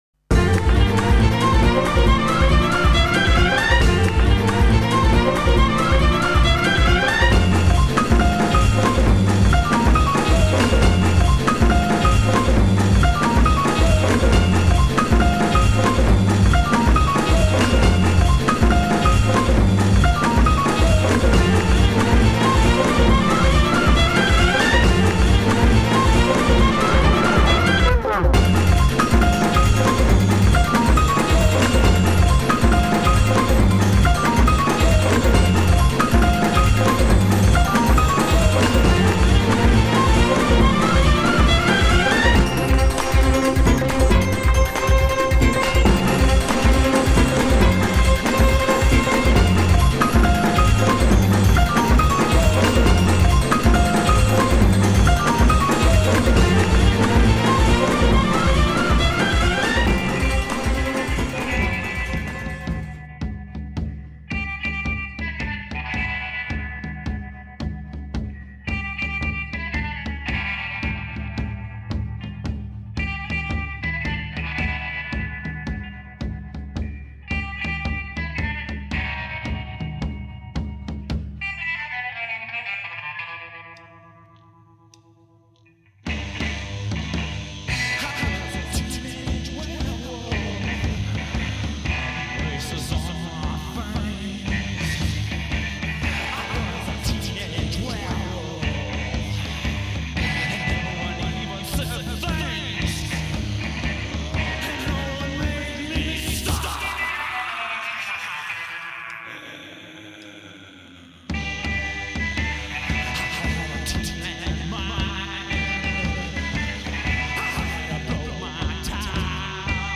garage rock